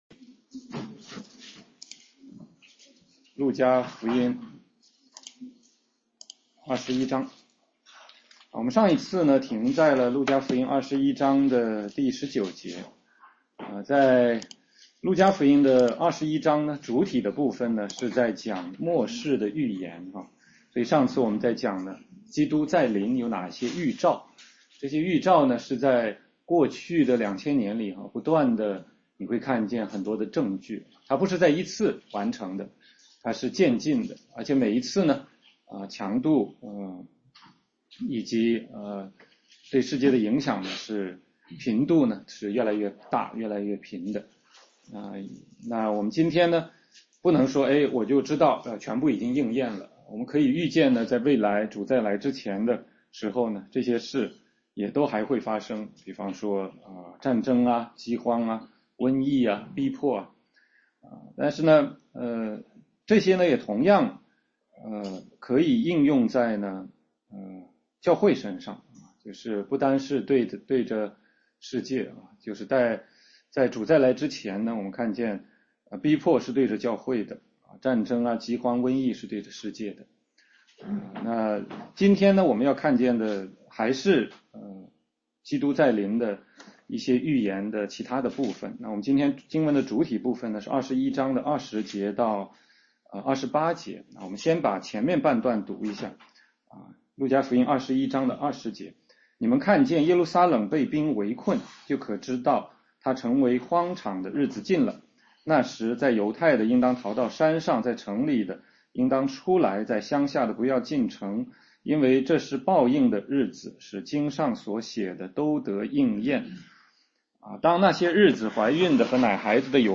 16街讲道录音 - 路加福音21章20-28节：耶稣预言耶路撒冷被毁和基督再来
全中文查经